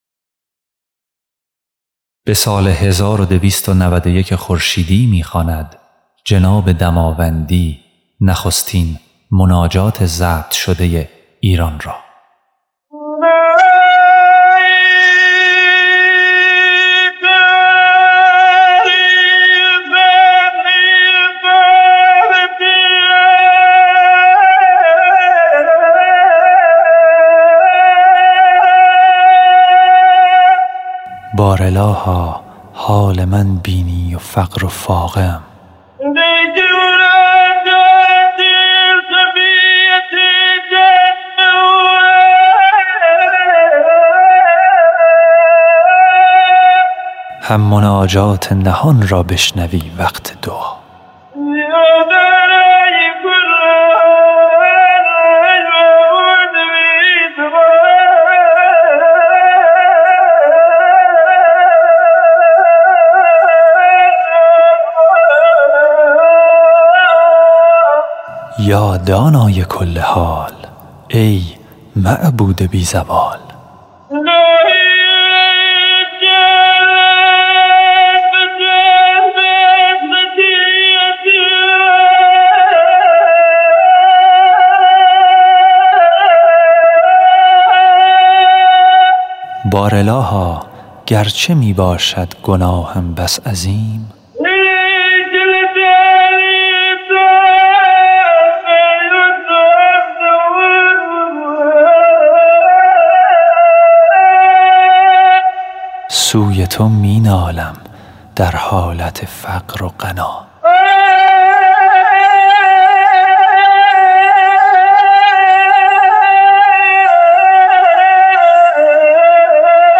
آواز شماره نوزدهم
خواننده